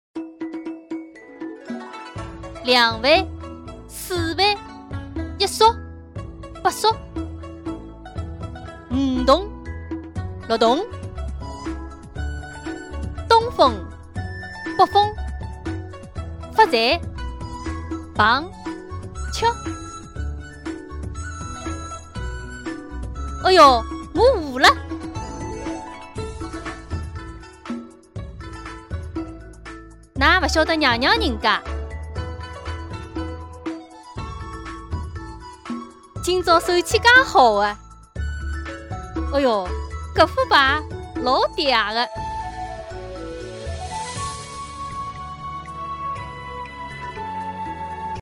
麻将配音demo